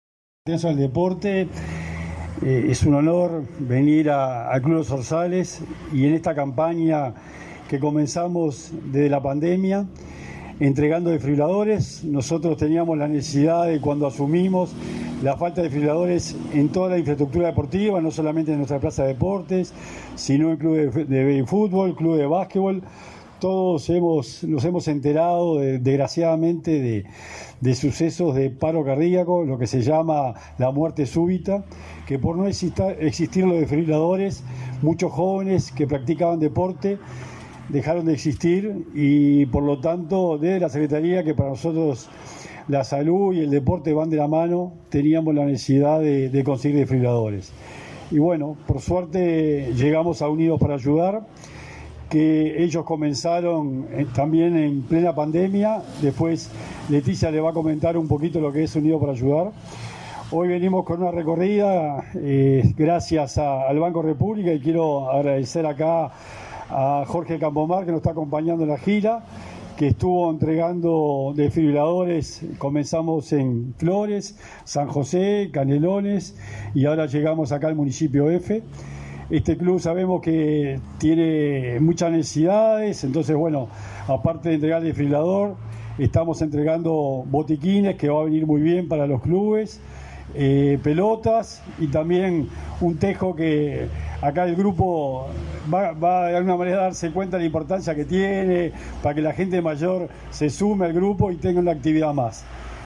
Palabras del secretario nacional del Deporte, Sebastián Bauzá